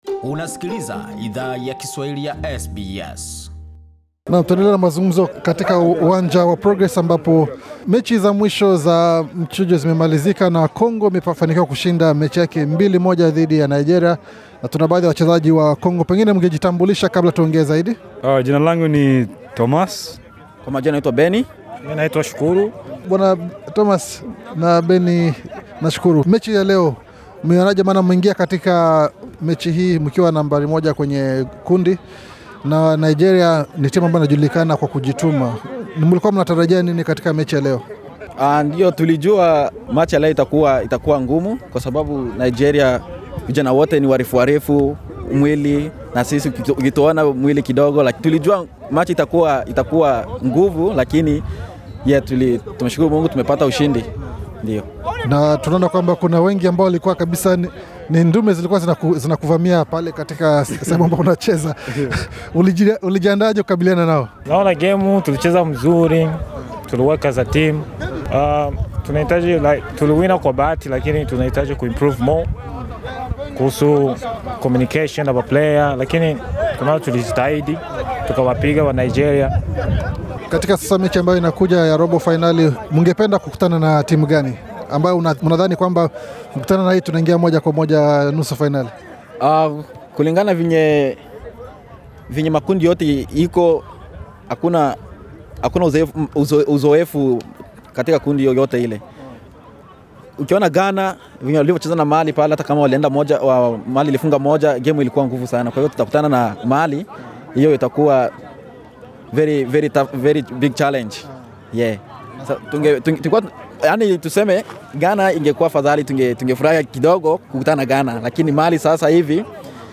Wachezaji wa timu ya DR Congo ya NSW wazungumza na SBS Swahili baada yakutinga robo fainali ya michuano ya kombe la Afrika la NSW Source: SBS Swahili